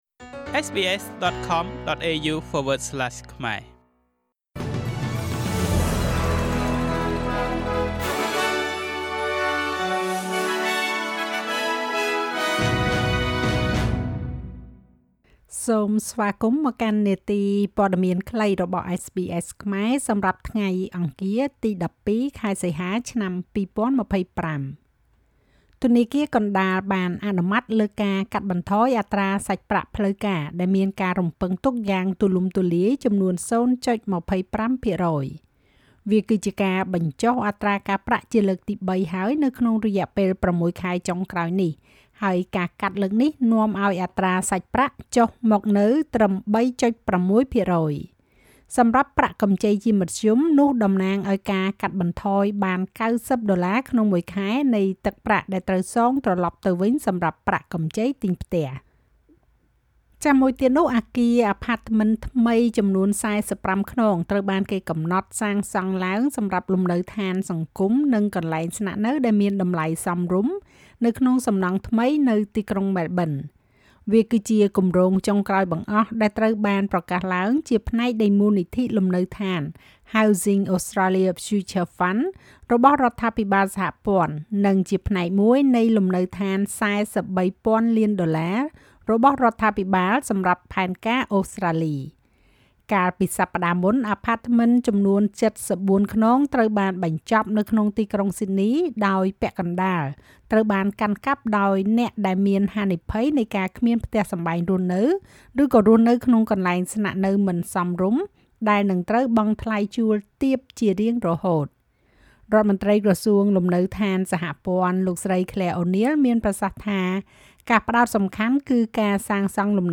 នាទីព័ត៌មានខ្លីរបស់SBSខ្មែរ សម្រាប់ថ្ងៃអង្គារ ទី១២ ខែសីហា ឆ្នាំ២០២៥